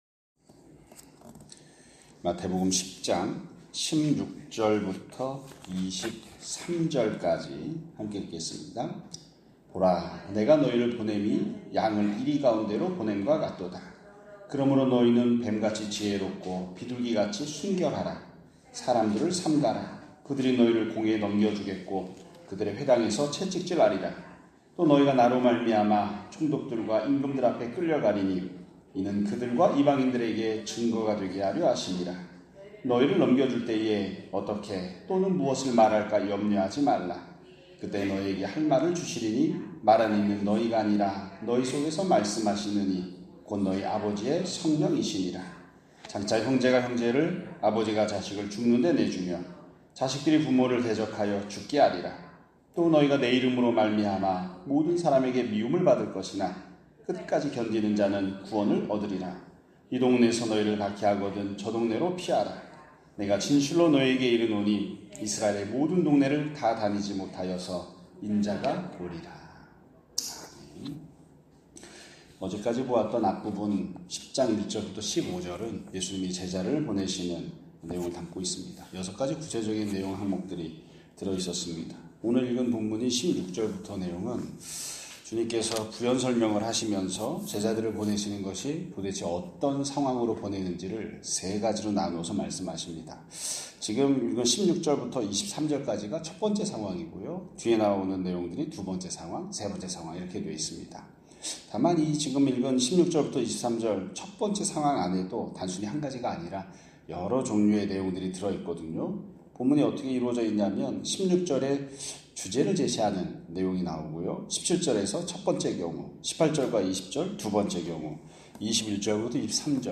2025년 8월 7일 (목요일) <아침예배> 설교입니다.